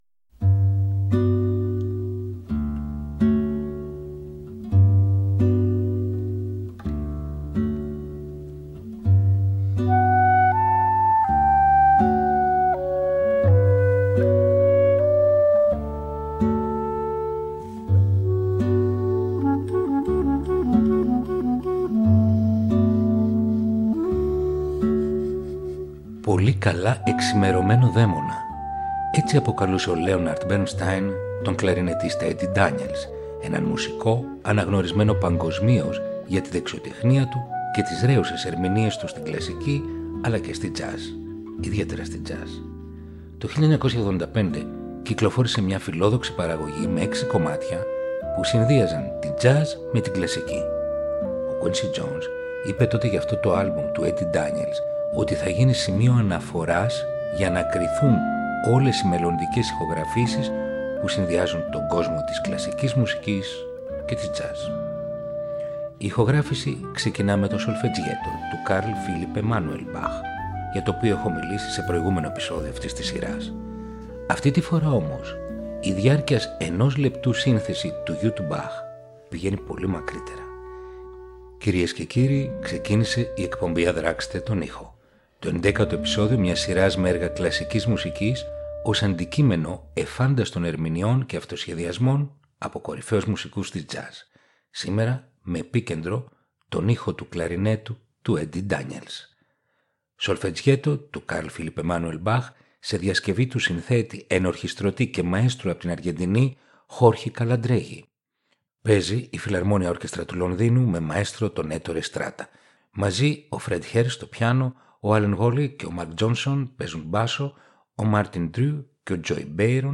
Η τζαζ συναντά την κλασσική μουσική – Επεισόδιο 11ο